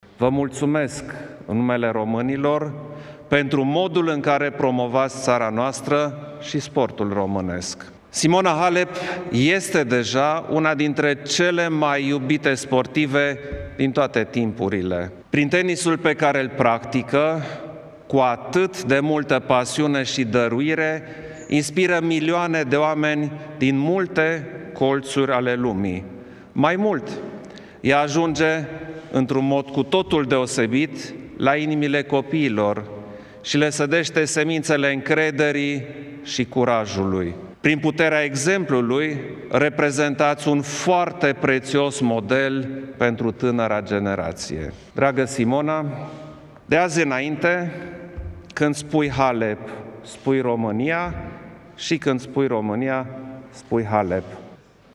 Simona Halep, primul român care câştigă turneul de tenis de la Wimbledon a fost decorată în urmă cu puțin timp la Palatul Cotroceni de preşedintele Klaus Iohannis cu Ordinul Naţional ”Steaua României” în grad de cavaler.
Președintele i-a mulțumit pentru performanța obținută , dar și pentru faptul că a devenit un model pentru copiii din România: